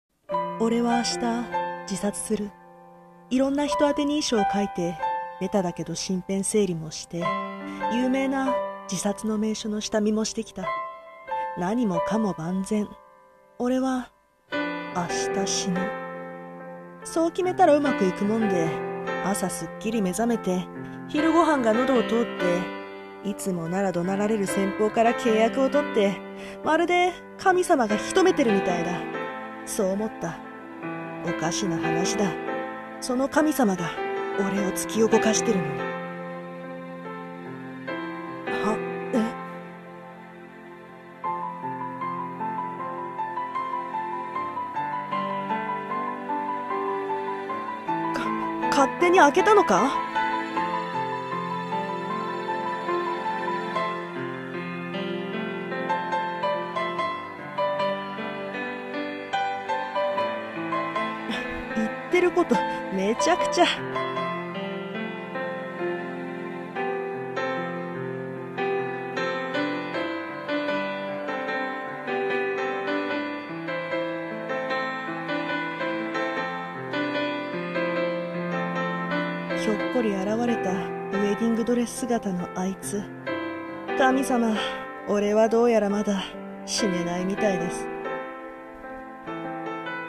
二人声劇】死がふたりを分かつまで？